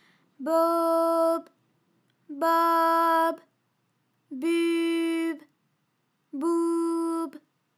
ALYS-DB-001-FRA - First, previously private, UTAU French vocal library of ALYS
bobaububoub.wav